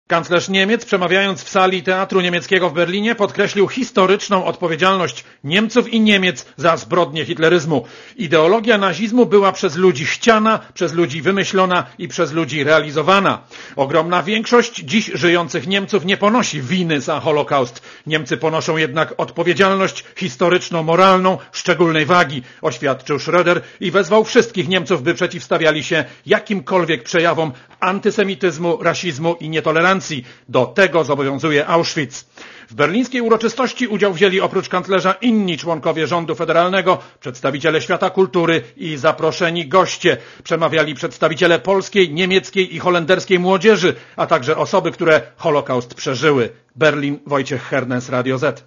Korespondencja z Berlina Obchody wyzwolenia KL Auschwitz odbędą się 27 stycznia w Krakowie i Oświęcimiu, dokładnie 60 lat po wkroczeniu do obozu pierwszych żołnierzy radzieckich.